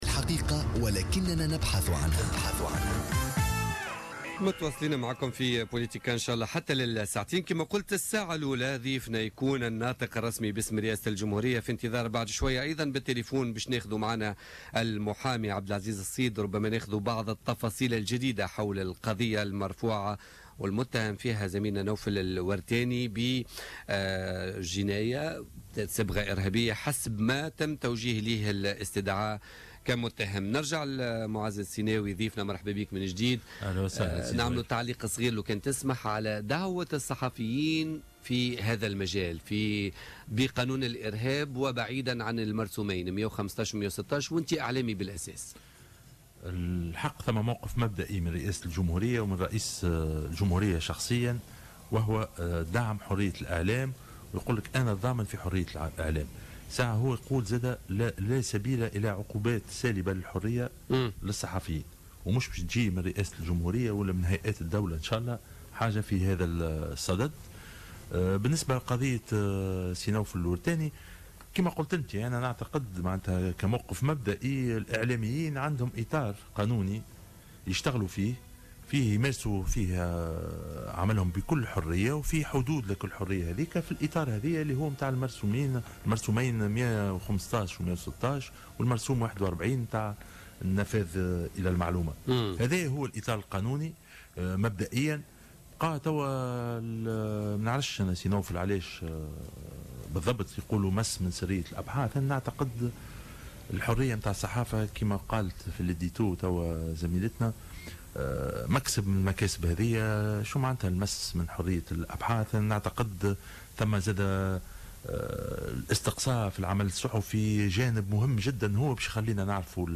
علق الناطق بإسم رئاسة الجمهورية معز السيناوي ضيف بوليتيكا اليوم الثلاثاء 9 فيفري 2016 على دعوة الصحفيين للمثول أمام القضاء ومحاسبتهم وفقا لقانون الإرهاب مؤكدا أن هناك موقف مبدئي لرئيس الجمهورية وهو دعم حرية الإعلام .